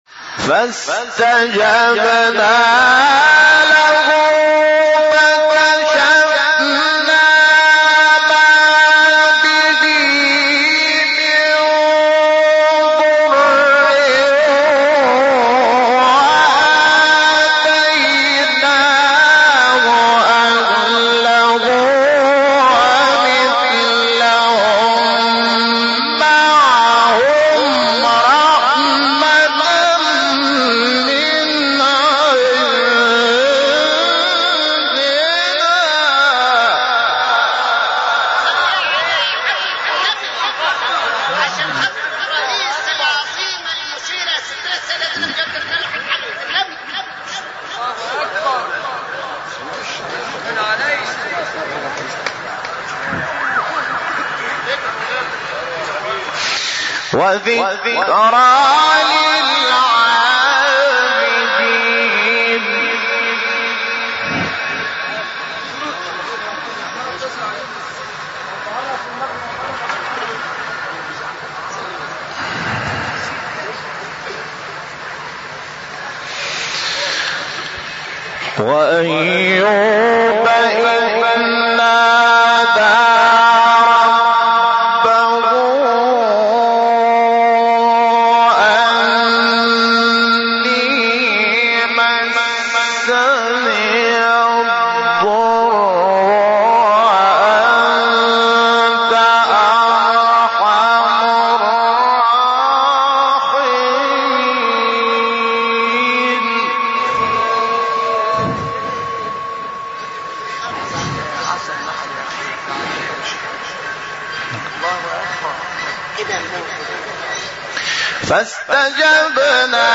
سوره : انبیاء آیه : 84-87 استاد : محمود شحات مقام : بیات قبلی بعدی